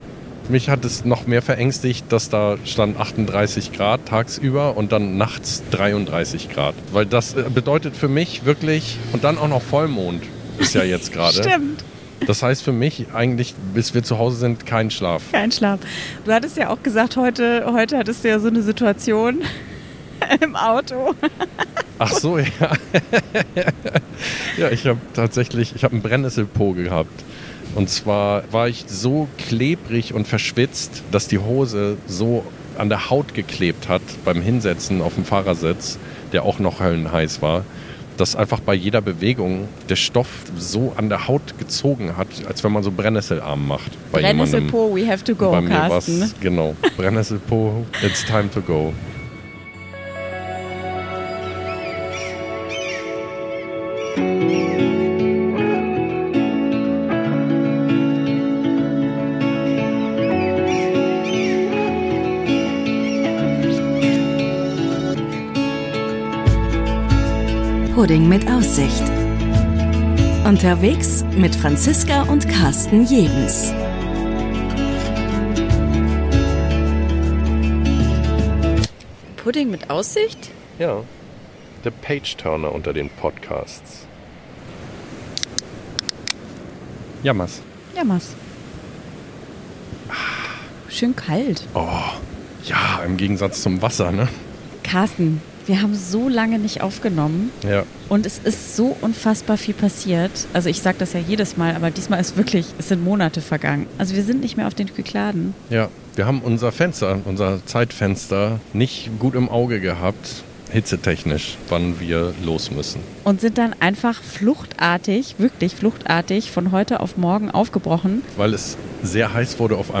Beschreibung vor 8 Monaten Zurück auf dem Festland greifen wir dieses Mal in ungewohntem Terrain zu den Mikros. Mit Blick auf menschliche Bojen und Rüdika-Mobile berichten wir von den abenteuerlichen letzten Wochen auf den Kykladen. Es geht um das Robinson-Crusoe-Feeling einer verschnarchten Insel, die unglaublichen Steuerkünste eines Fährkapitäns, glühende Saharawinde, den besten Salat aller Zeiten und durchzechte Vollmondnächte mit netten Amerikanern.